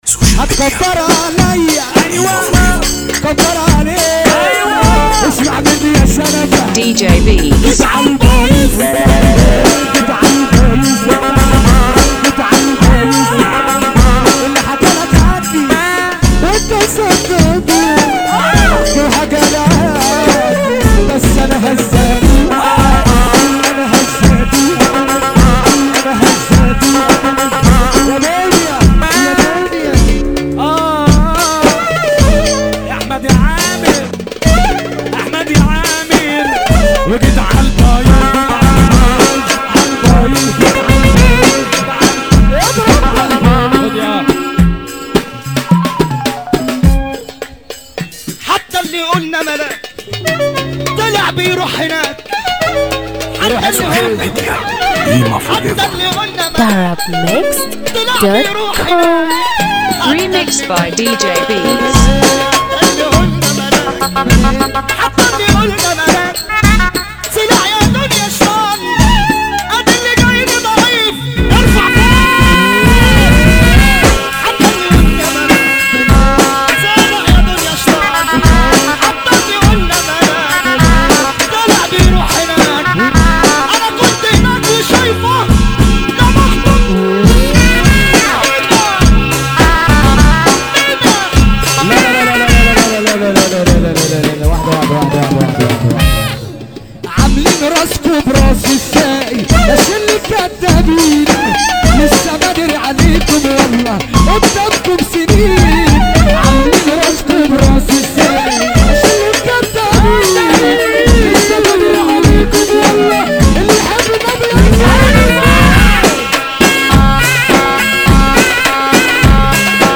دويتو
اورج